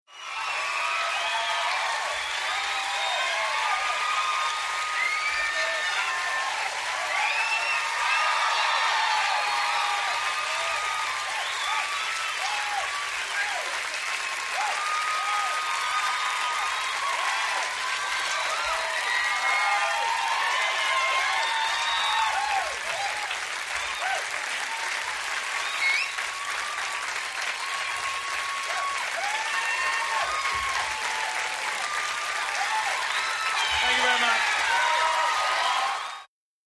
Club applause, whistles & whoops